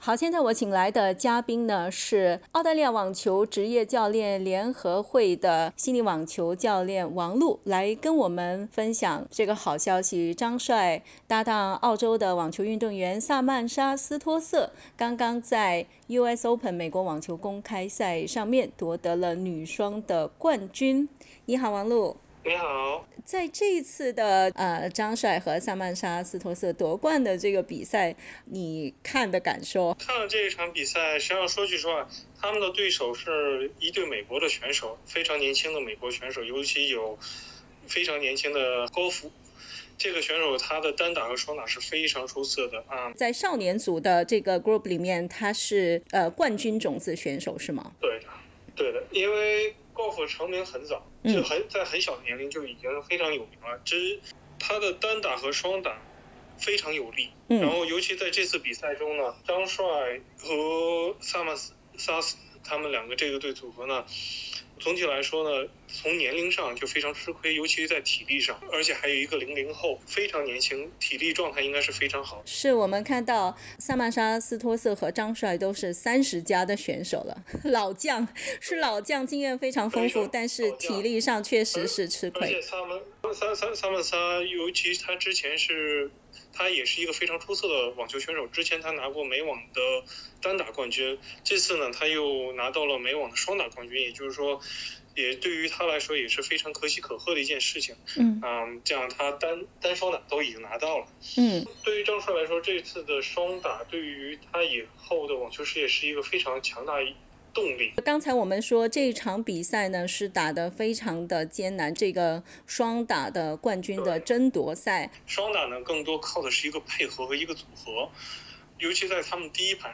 在2021年美国网球公开赛最后一个比赛日上，张帅（中国）和萨曼莎.斯托瑟（澳大利亚）这对组合以2比1战胜美国组合高芙和麦克纳利，获得双打冠军。（点击上图收听采访录音）